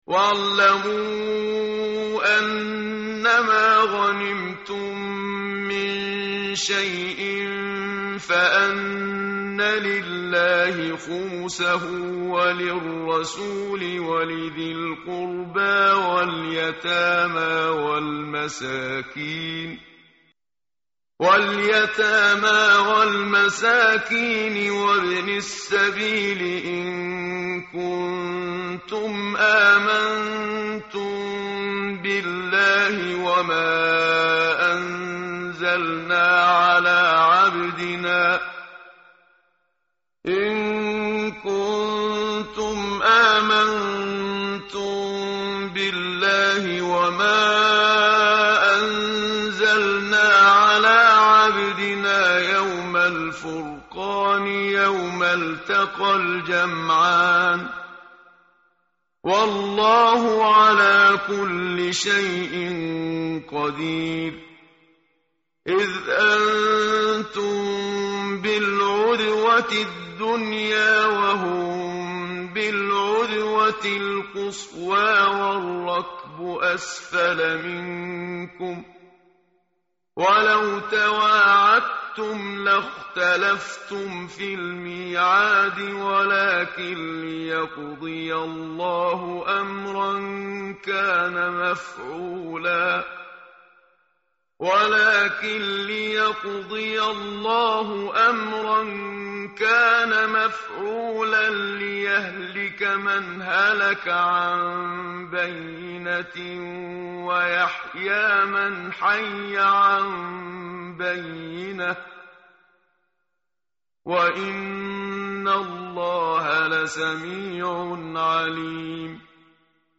tartil_menshavi_page_182.mp3